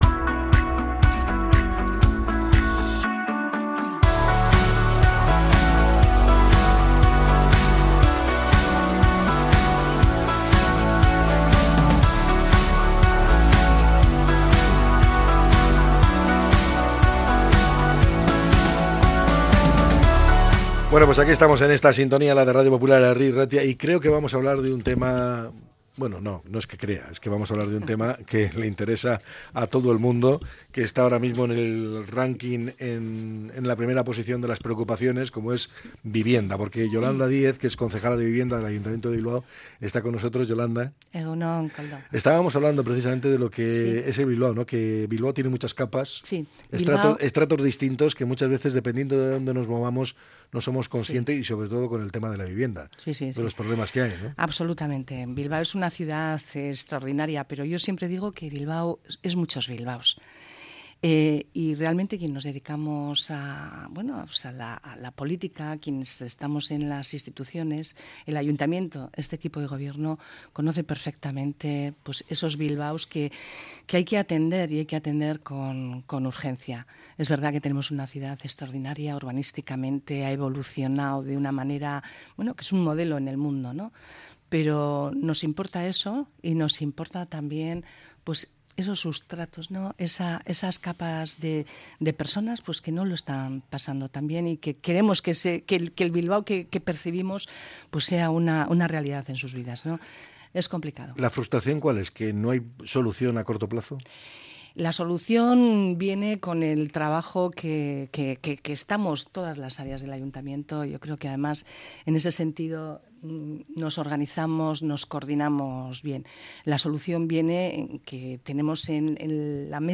Yolanda Díez, concejala de Vivienda del Ayuntamiento de Bilbao, ha participado en el programa EgunOn Bizkaia.